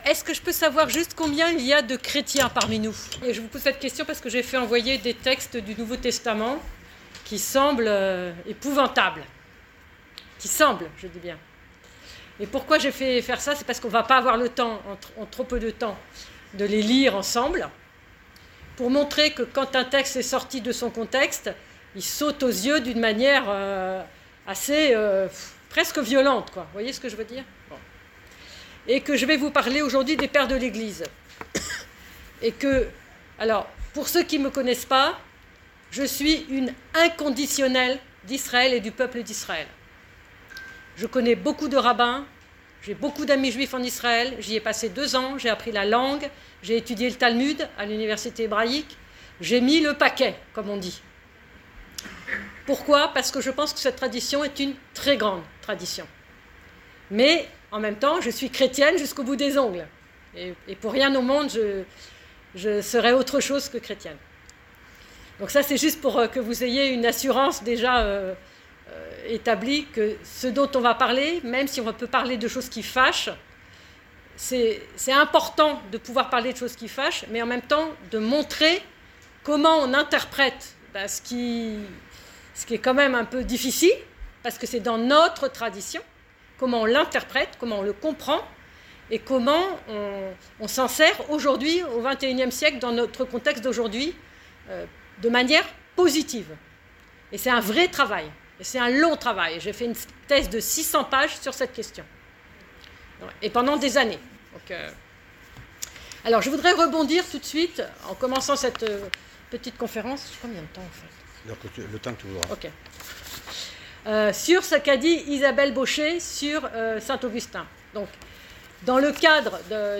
Conférence donnée dans le cadre de l'AJCF du Bassin d'Arcachon qui présente les Pères de l'Église dans le cadre du dialogue Judéo-Chrétien. La difficulté pour les chrétiens qui cherchent à retrouver leurs racines juives est qu'ils se trouvent confrontés à des paroles violentes des Pères de l'Église concernant les juifs ou le judaïsme en général.